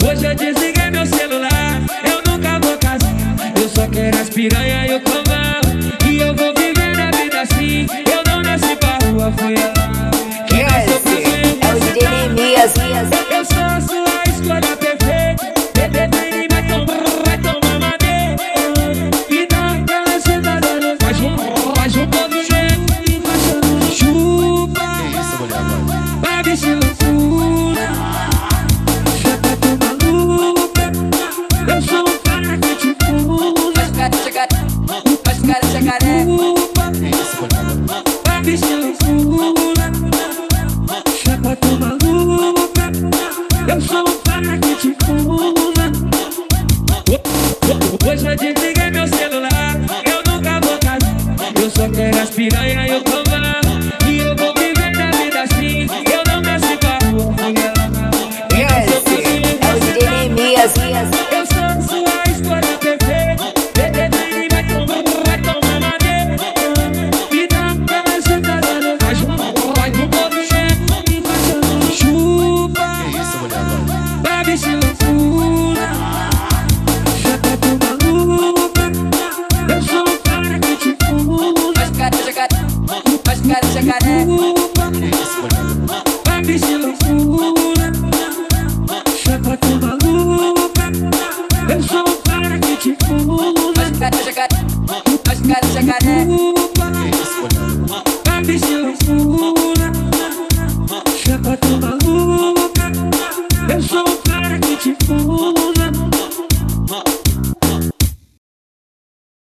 Página inicial Funk